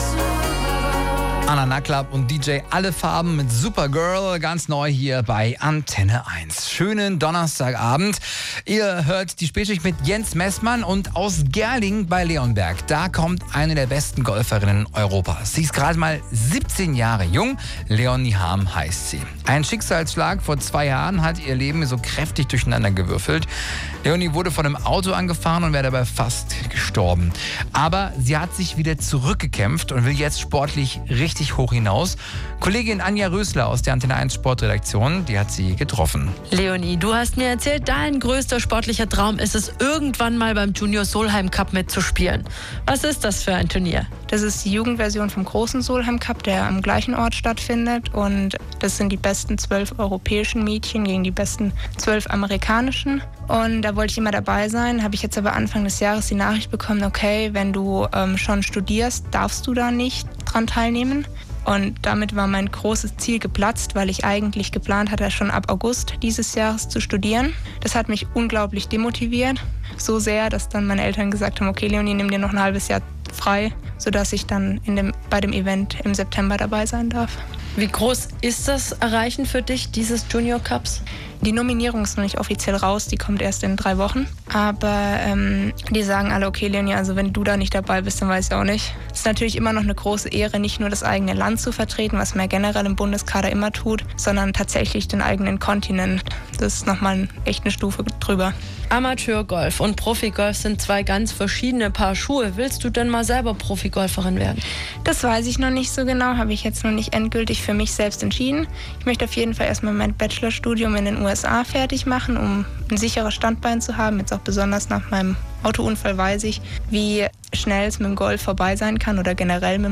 Radiointerview
Interview